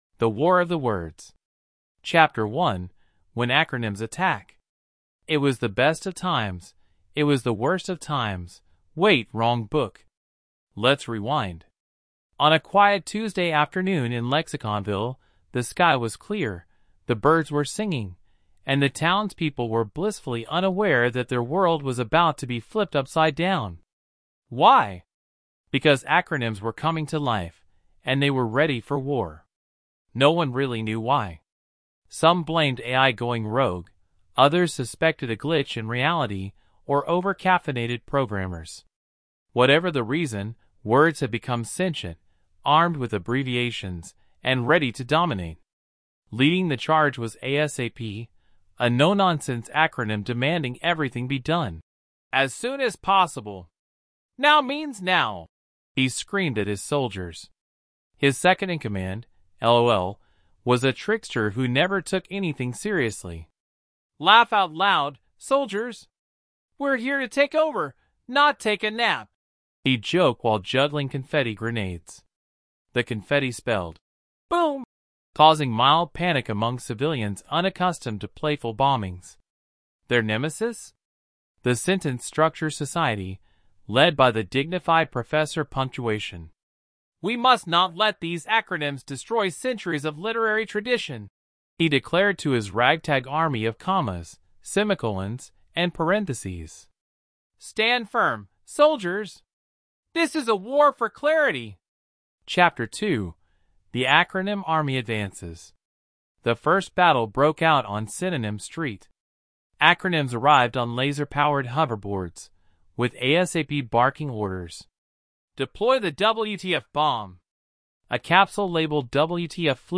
Thanks AI